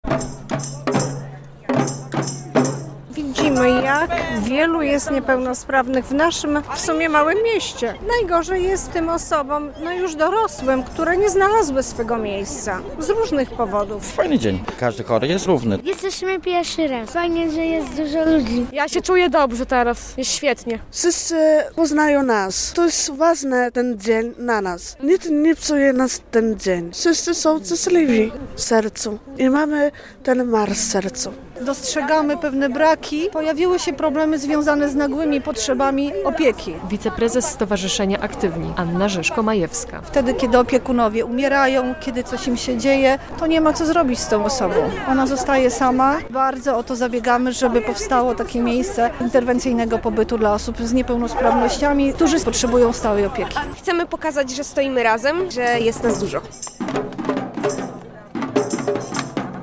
Ulicami Białegostoku przeszedł XXIII Marsz Godności Osób Niepełnosprawnych - relacja